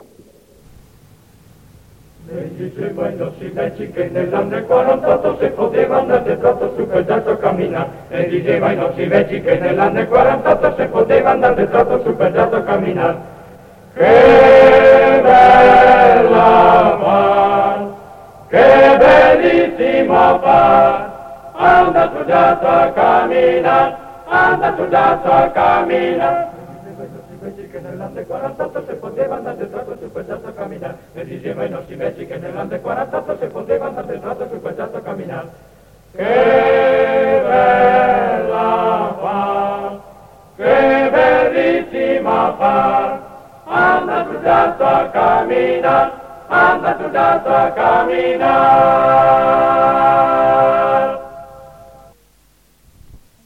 A.7.2.13 - Ne diseva i nòsi vèci (Coro della SAT, disco Columbia 1933)